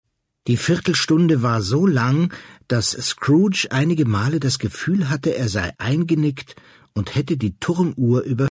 Felix von Manteuffel (Sprecher) Audio-CD 2016